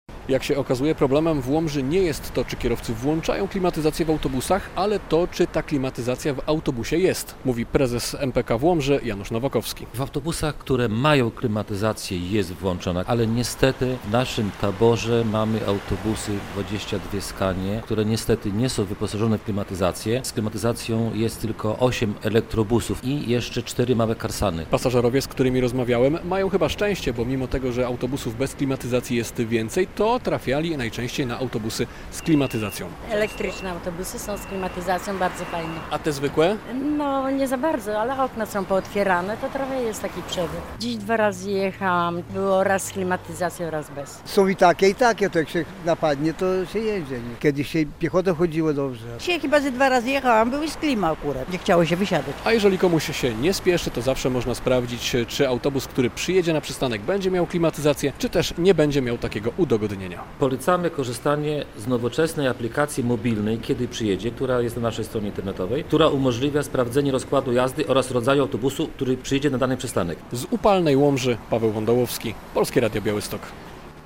Dlaczego nie we wszystkich autobusach w Łomży jest klimatyzacja? - relacja